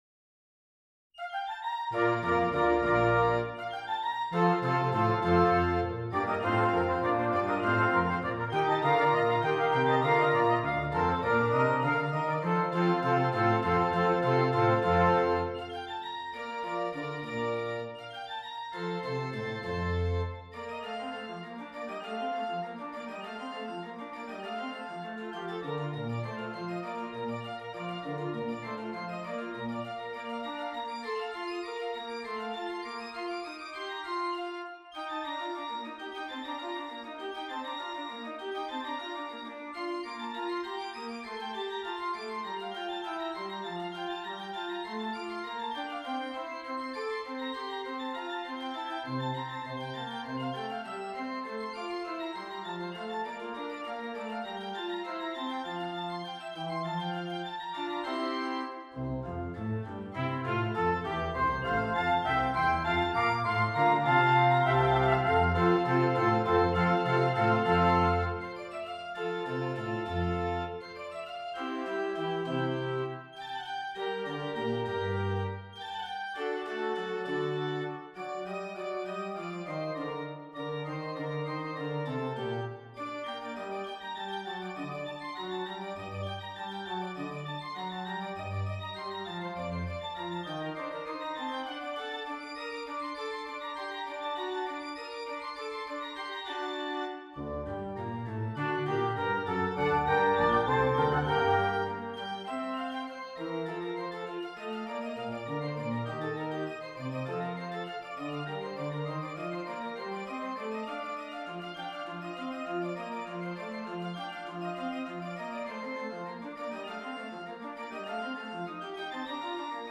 Brass Quintet and Organ